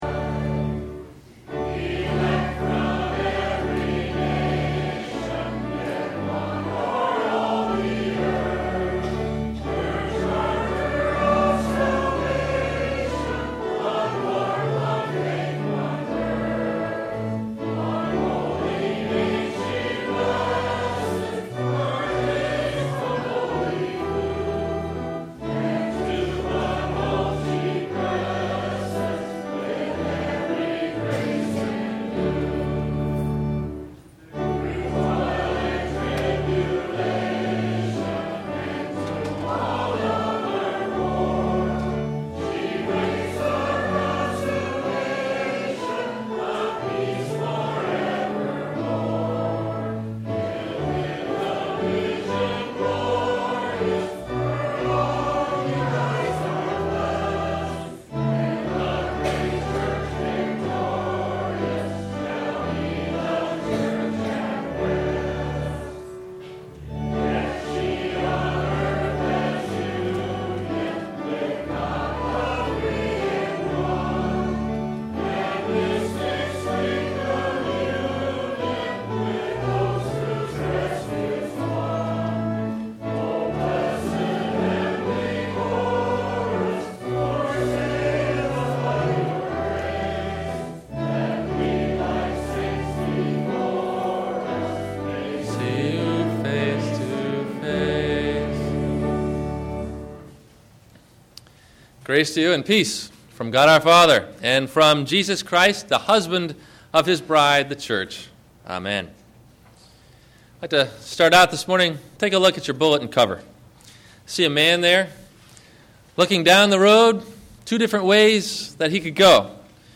Love with Honor – Sexuality - Sermon - February 08 2009 - Christ Lutheran Cape Canaveral